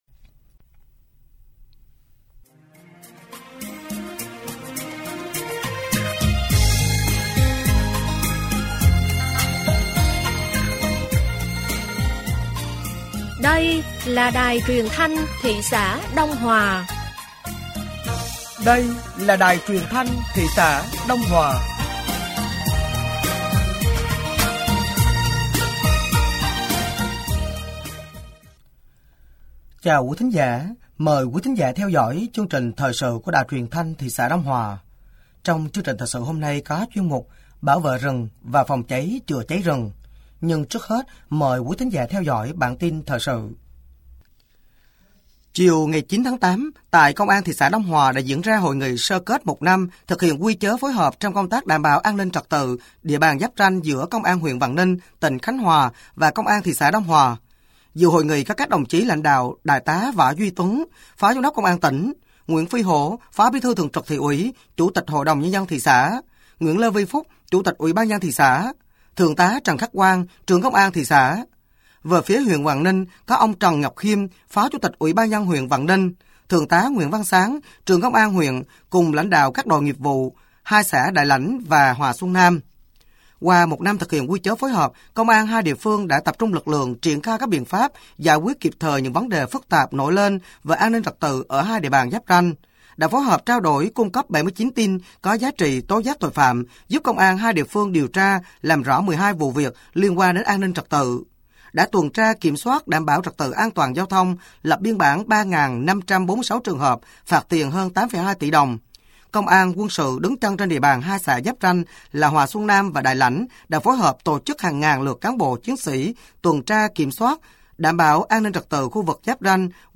Thời sự tối ngày 10 và sáng ngày 11 tháng 8 năm 2024